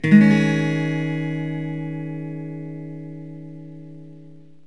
Gssus4.mp3